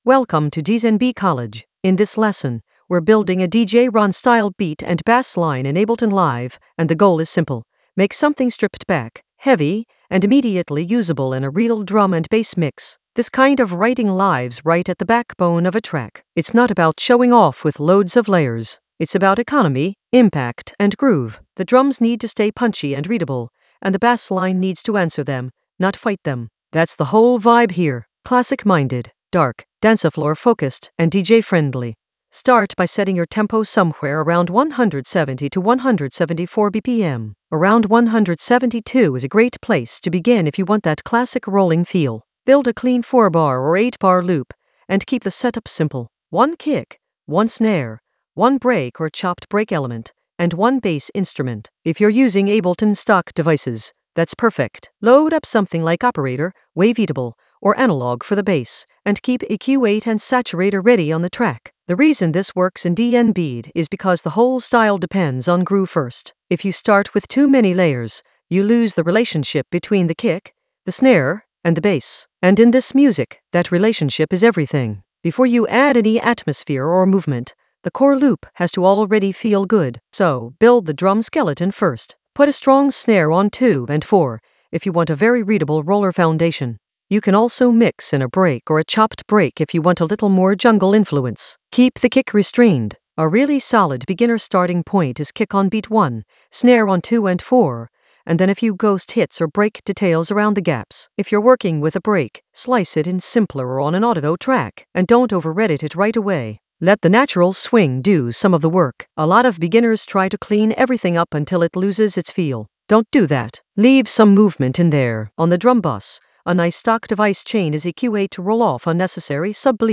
An AI-generated beginner Ableton lesson focused on DJ Ron beats and basslines in the Basslines area of drum and bass production.
Narrated lesson audio
The voice track includes the tutorial plus extra teacher commentary.